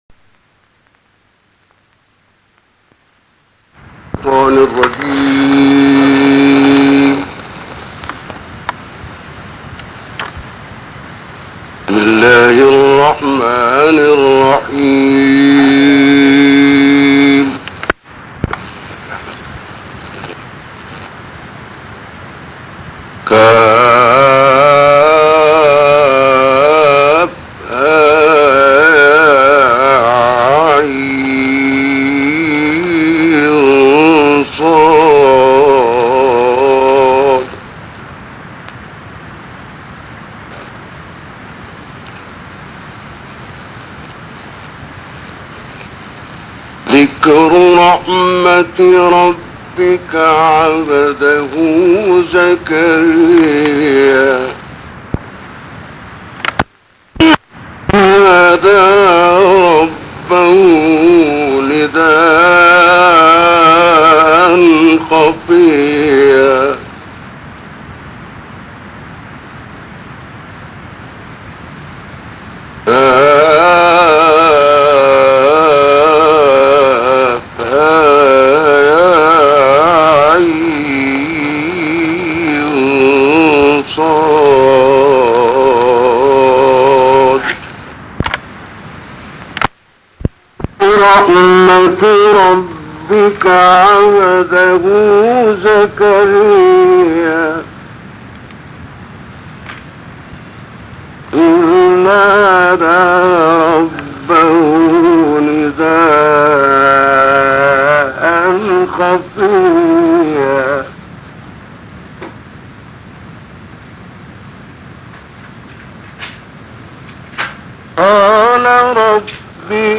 این تلاوت در کشور مصر اجرا شده است و مدت زمان آن 34 دقیقه است.